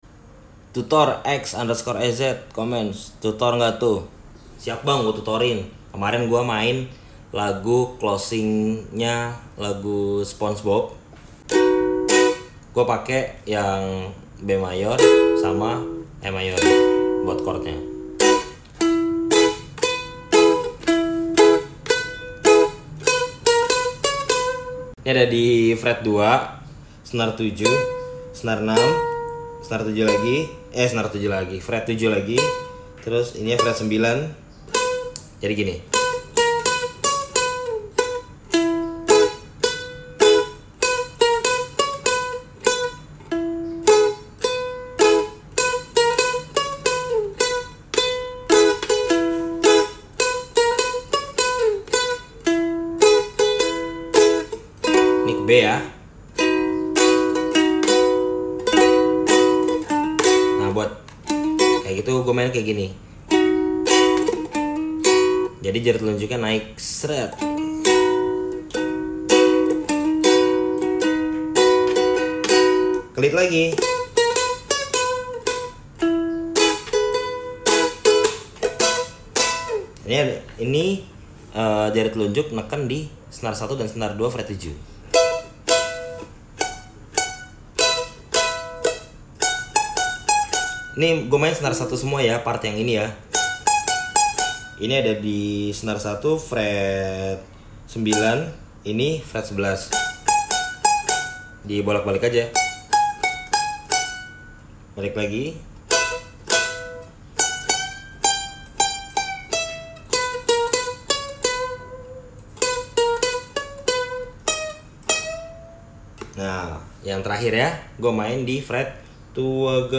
Ukulele